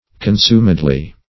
Search Result for " consumedly" : The Collaborative International Dictionary of English v.0.48: Consumedly \Con*sum"ed*ly\ (k[o^]n*s[=u]m"[e^]d*l[y^]), adv.